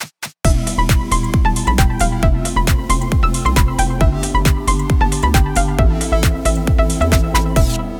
EmFG7Am
こんなふうになると、すっかり普段のマイナーキー音楽となってしまいました。“センター”の座がラによって奪われてしまっています。